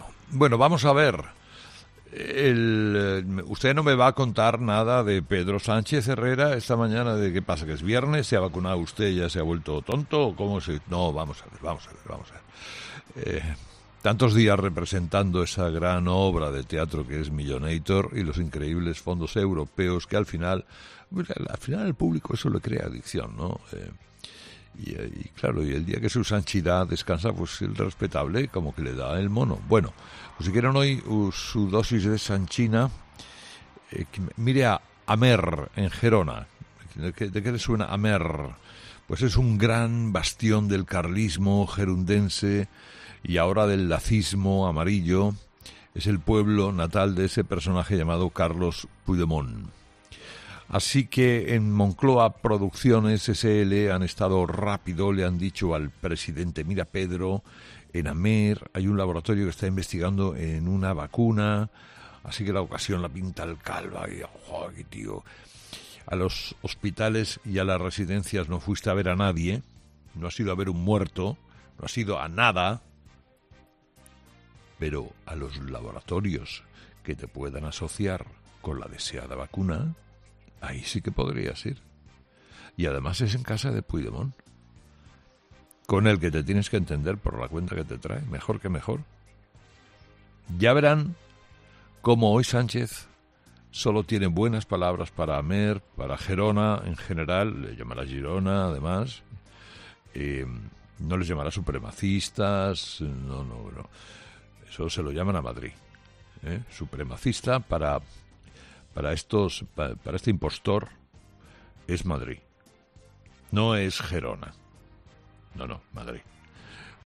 El presentador de 'Herrera en COPE', Carlos Herrera, ha arrancado su primer monólogo de las seis de la mañana haciendo referencia a las "expectativas de las vacunas", después de que él se haya vacunado este jueves con la primera dosis de AstraZeneca.